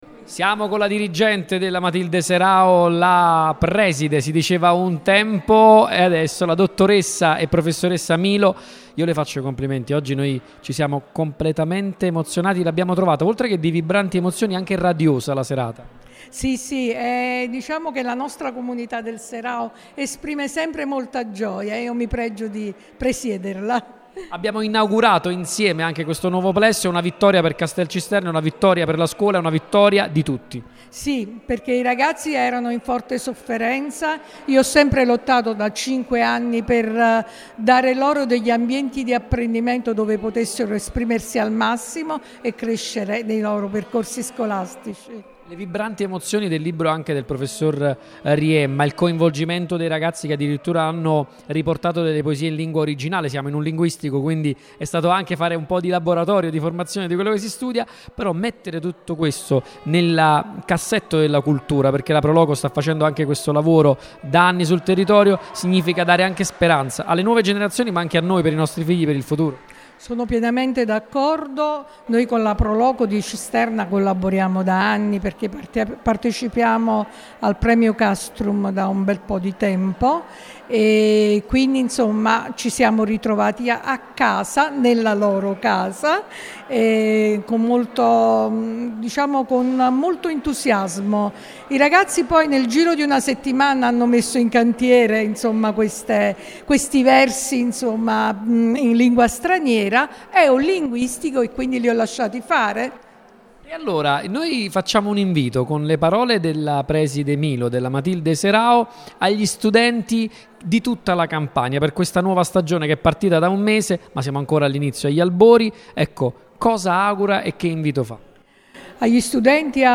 Radio Punto Nuovo, in qualità di radio partner dell’iniziativa, era presente alla presentazione e alla conferenza stampa, e sarà oggi e domani, 24 e 25 ottobre, presente con la regia mobile dall’Auditorium Enrico De Nicola, a partire dalle ore 18:00, per raccontare il convegno odierno e la premiazione dei vincitori.